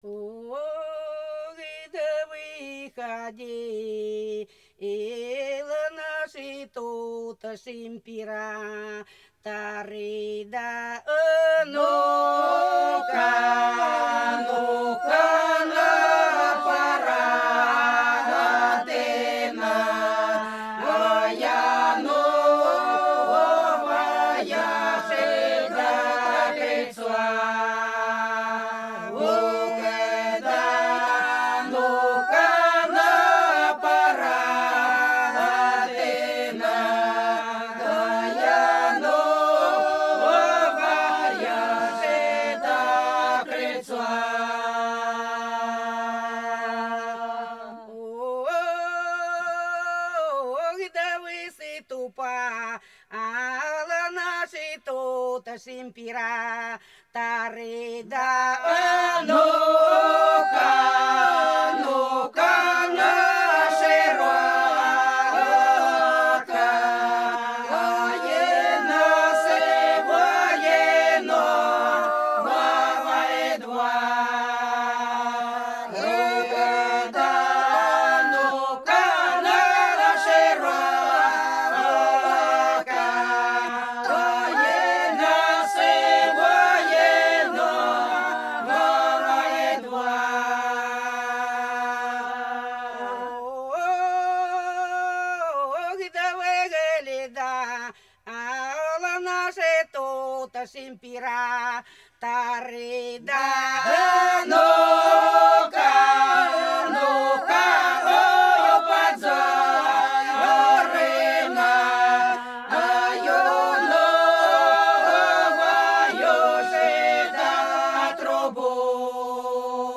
Пролетели все наши года Выходил наш император – историческая протяжная (Фольклорный ансамбль села Иловка Белгородской области)